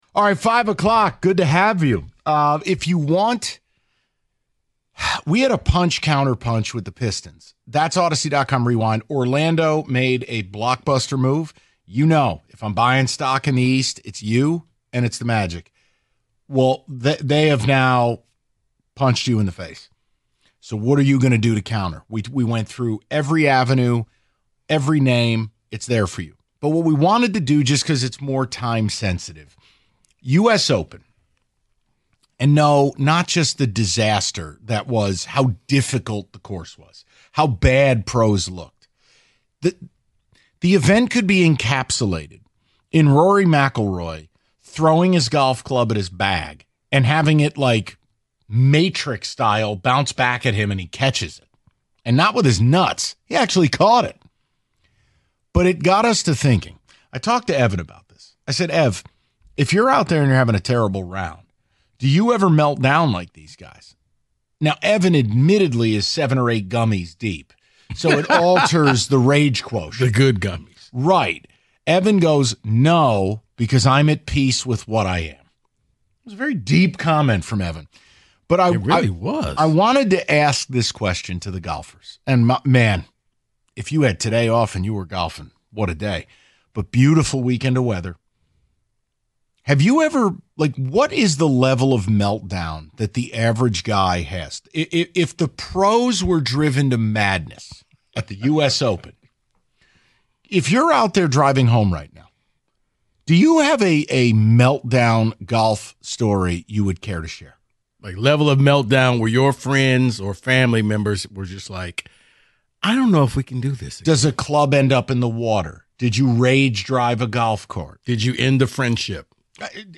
They open up the phone lines to hear if you could relate to the golfers at Oakmont.